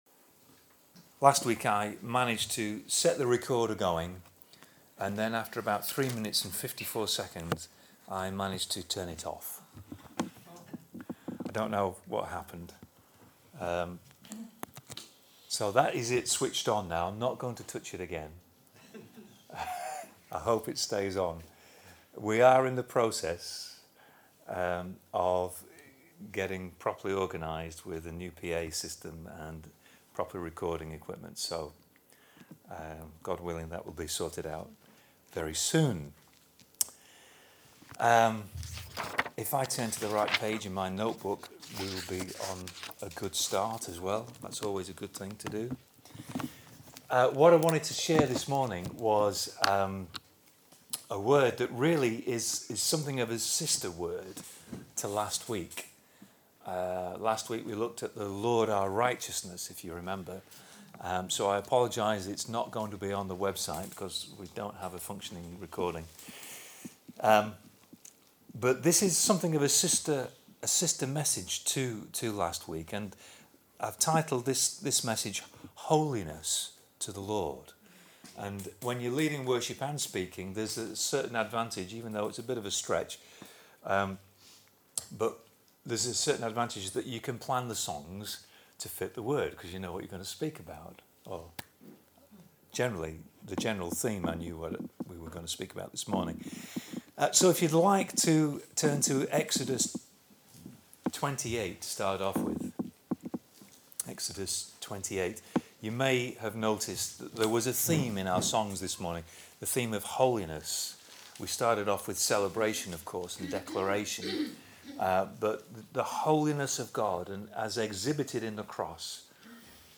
Here is the message from Sunday 1st July: